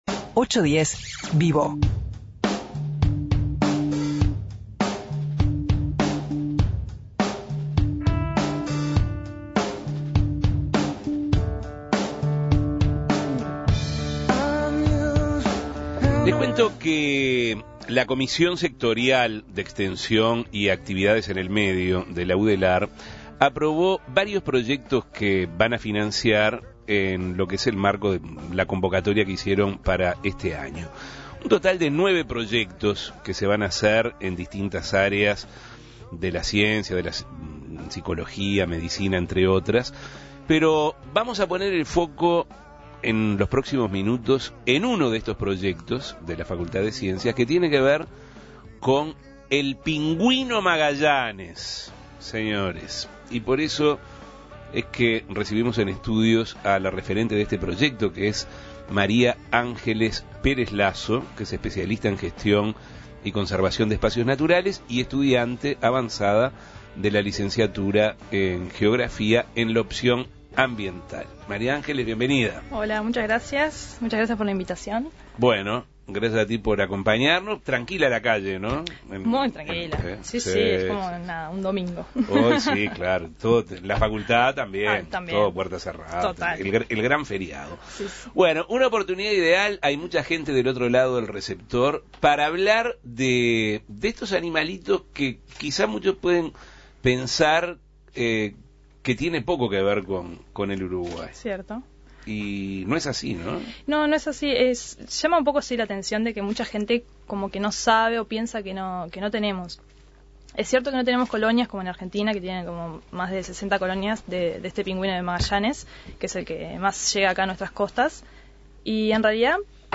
recibimos en estudios